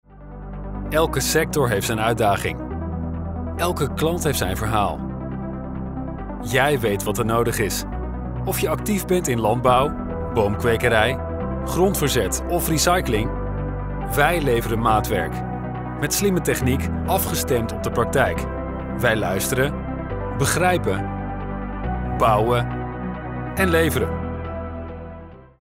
Unternehmensvideos
Niederländische Sprecherstimme: Business, Zuverlässig & Positiv
Meine Stimme ist geschäftlich, zugleich zugänglich, zuverlässig und positiv.
Ich nehme in meinem eigenen professionellen Heimstudio auf, um erstklassigen, sendetauglichen Sound zu liefern.
Mikrofon: Neumann TLM 103
Kabine: Studioguys Akustik-Isolationskabine (trockener Sound)
VertrauenswürdigKonversationFreundlichWarmErfahrenZuverlässigUnternehmenPositiv